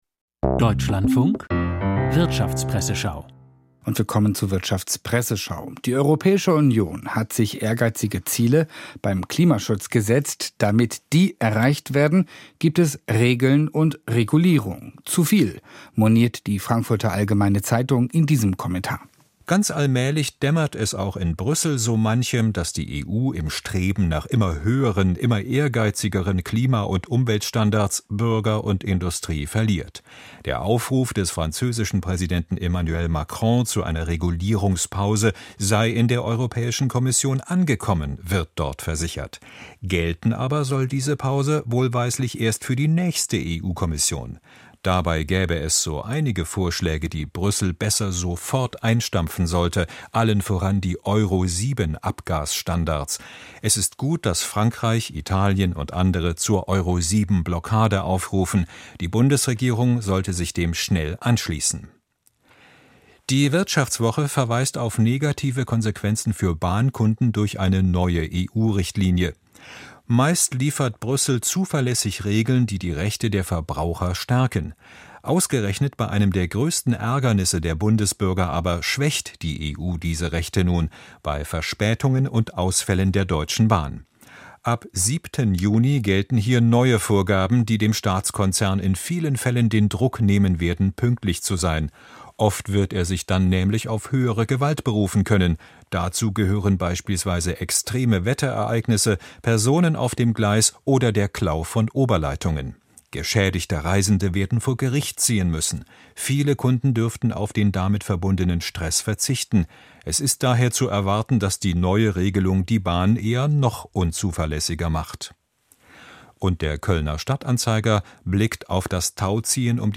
Die Wirtschaftspresseschau